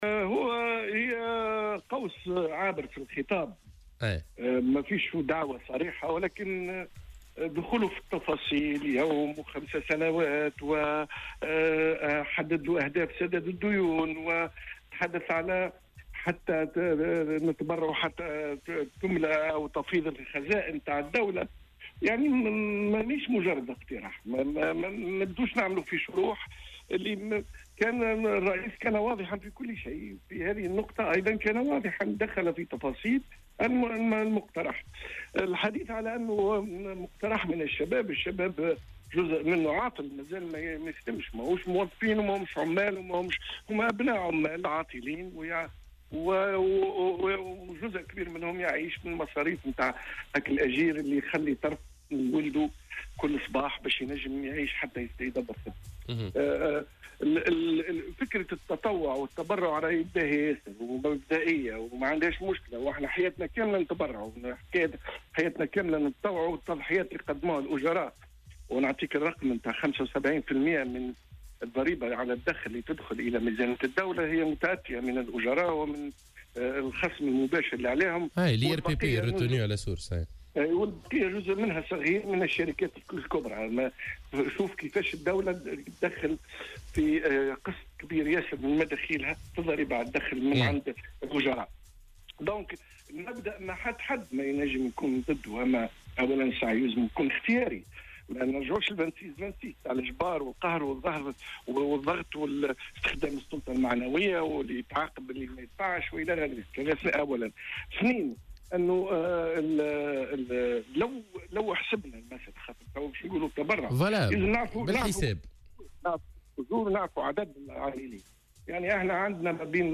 في مداخلة له اليوم في برنامج "صباح الورد" على "الجوهرة أف أم"